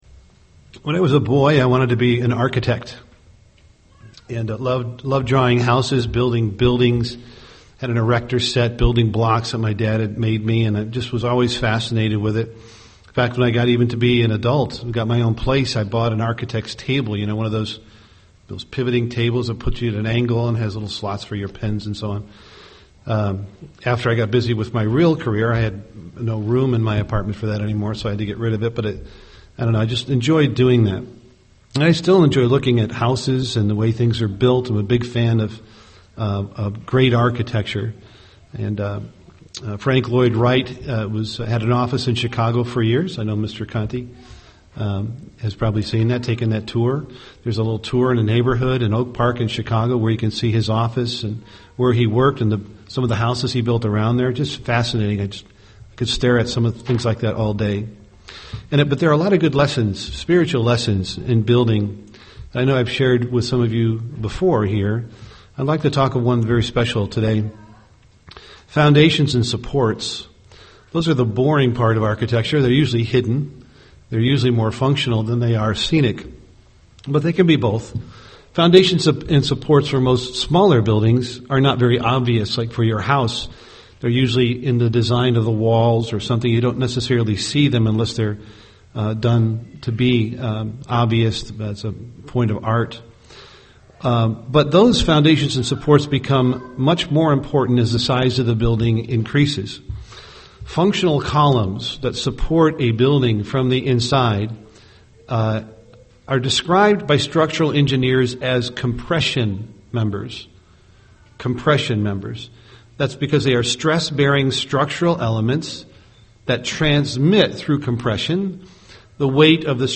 UCG Sermon Cornerstone Studying the bible?